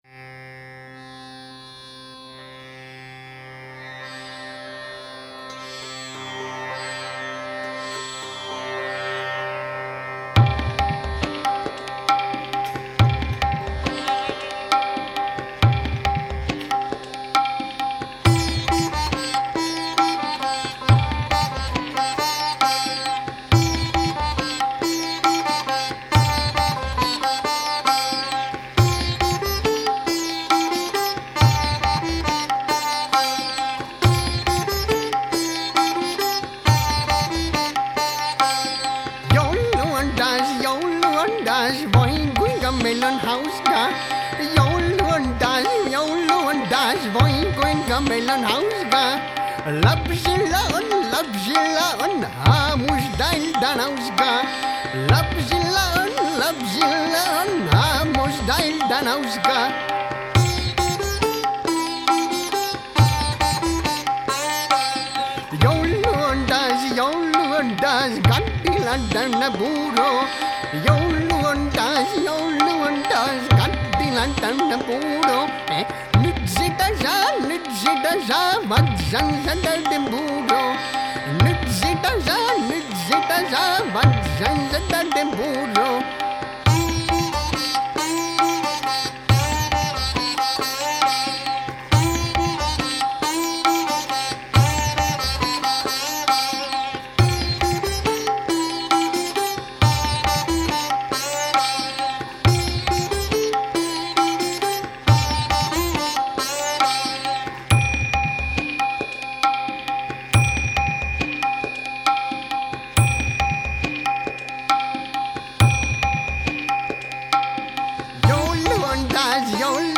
liidd voucls, prcashn
zitar, programing